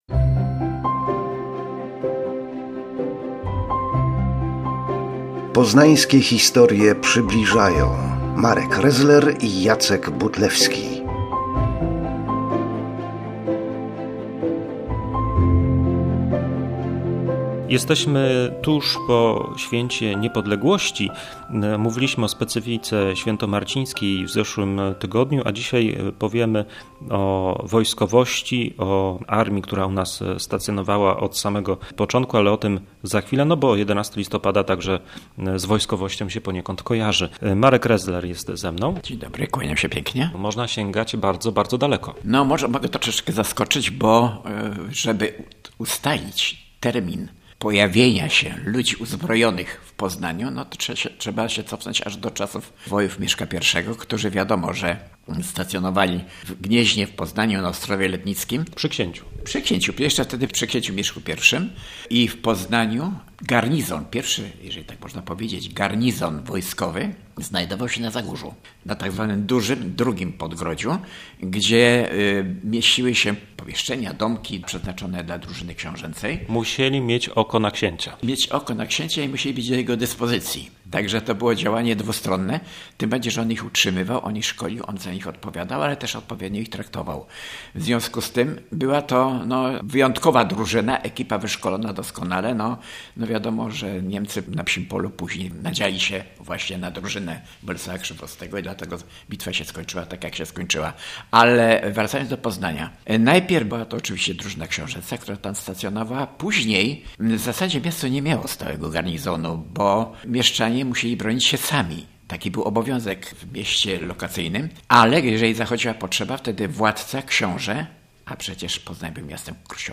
Od kiedy w Poznaniu stacjonuje wojsko? O tym w rozmowie